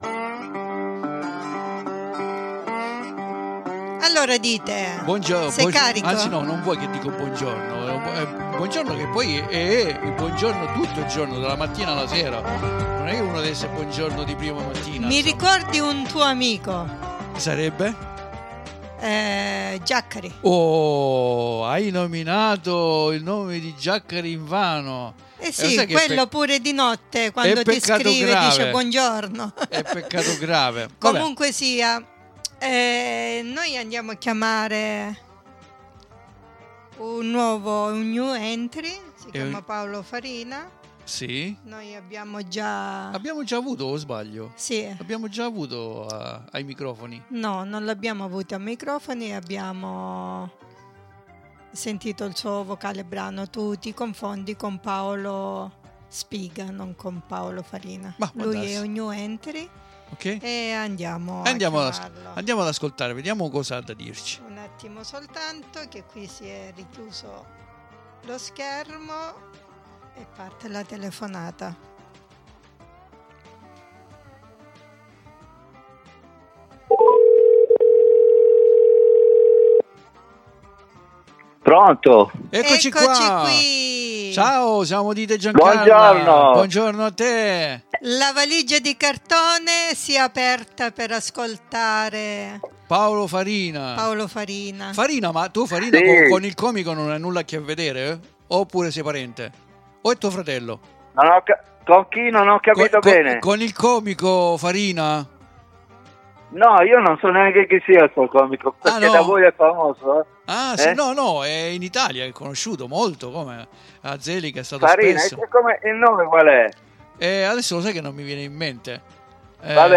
IO NON VORREI SVELARE TROPPO, QUINDI VI INVITO AD ASCOLTARE LA SUA INTERVISTA CONDIVISA QUI IN DESCRIZIONE .